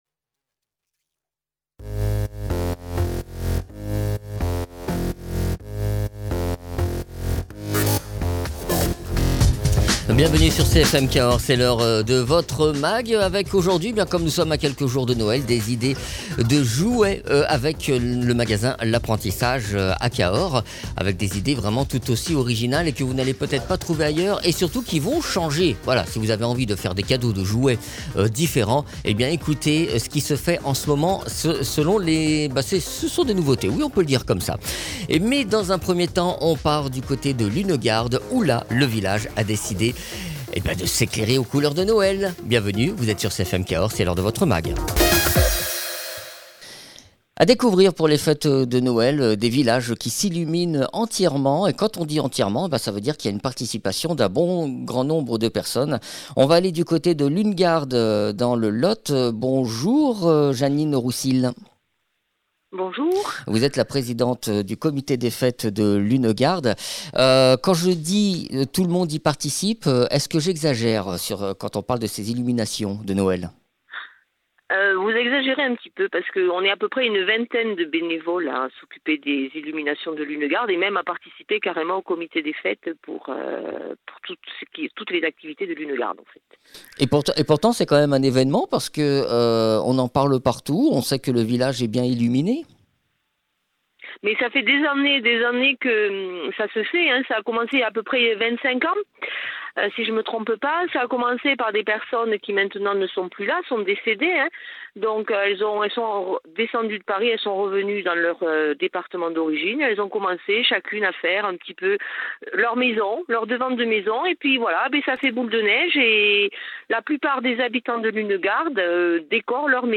Le Mag Cahors 651